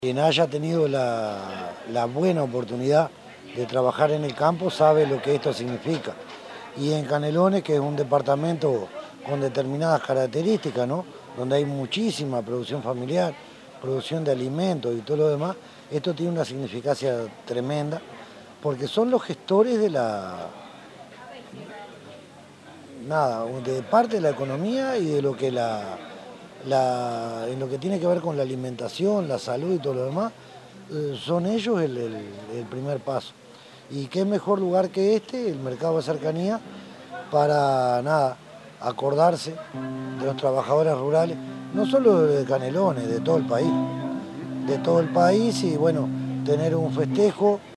El Alcalde de Atlántida, Gustavo González, se refirió a la importancia de la fecha para Canelones.
gustavo_gonzalez_-_alcalde_municipio_de_atlantida.mp3